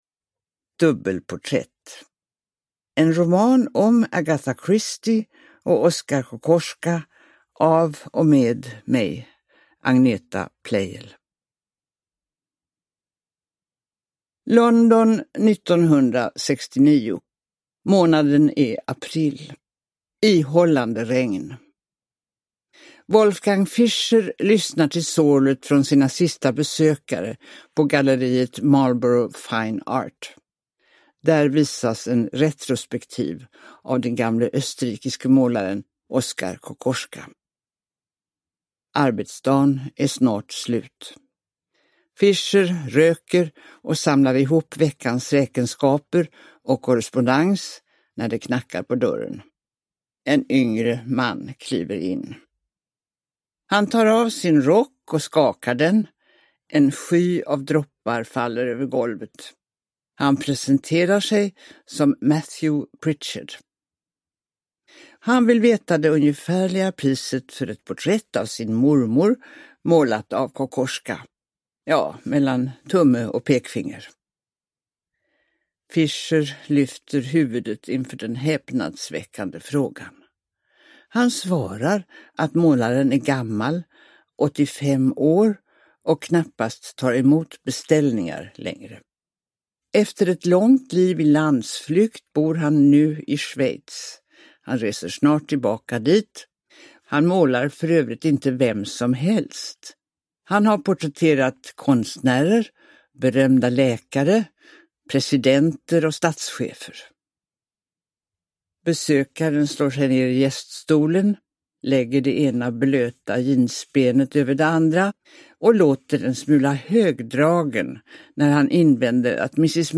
Dubbelporträtt : en roman om Agatha Christie och Oskar Kokoschka – Ljudbok – Laddas ner